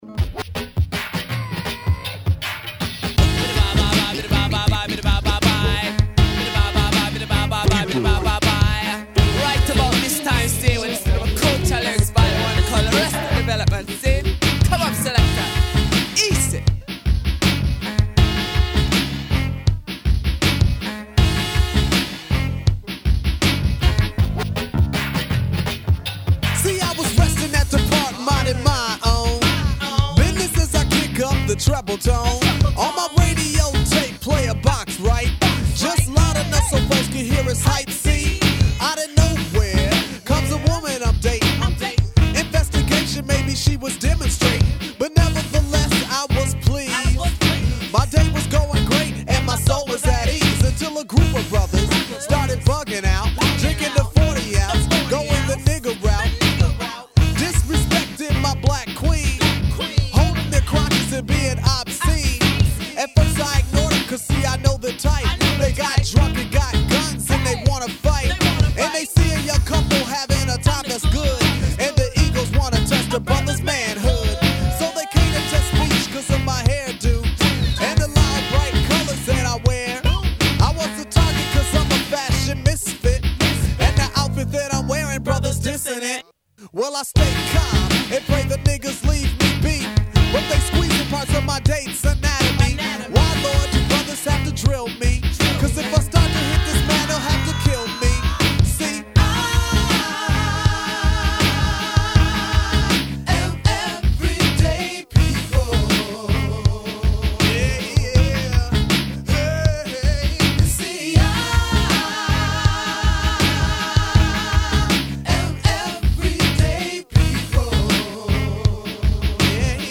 TEMPO: 100
Morceau en G majeur (1b)
Version Album Dec 04